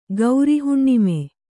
♪ gauri huṇṇime